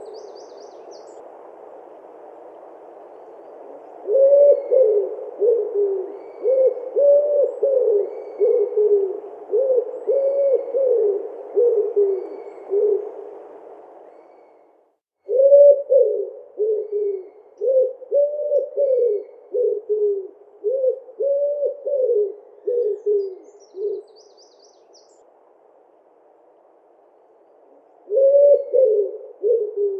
Pigeon ramier - Mes zoazos
pigeon-ramier.mp3